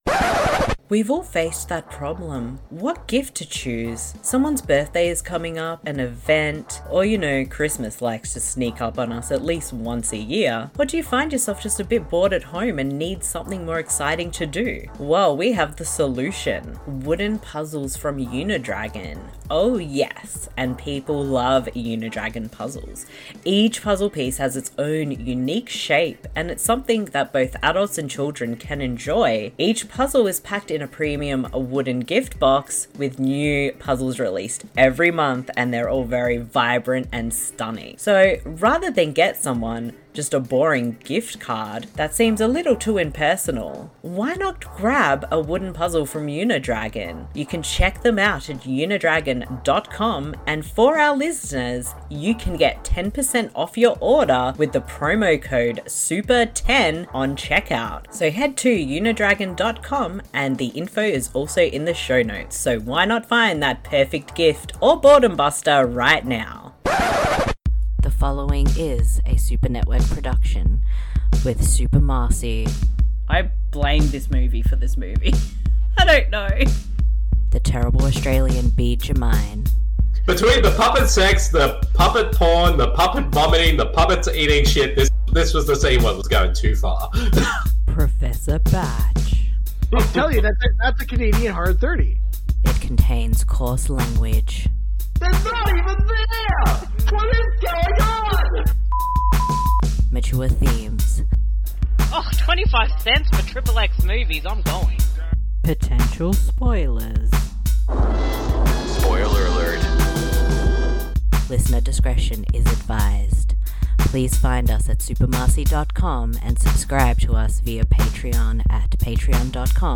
This podcast series is focused on discovering and doing commentaries for films found on the free streaming service Tubi, at TubiTV